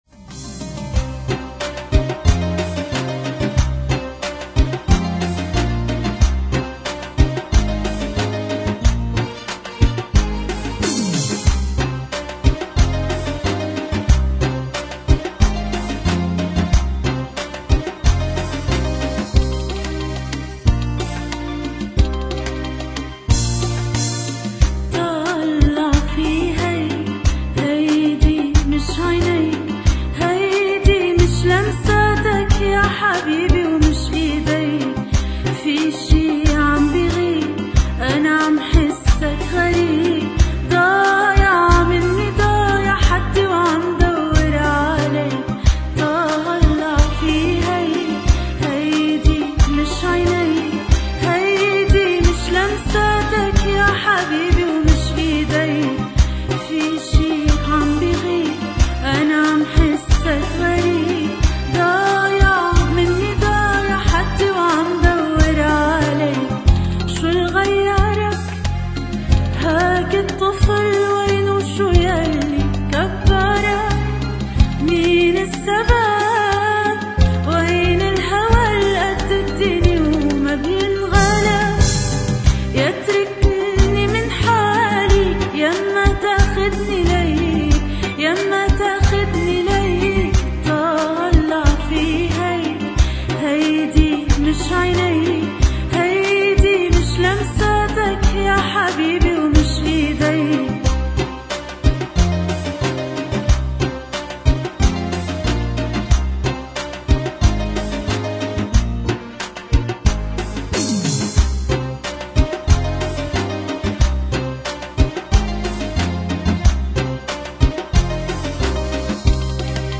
Arabic Karaoke Talented Singers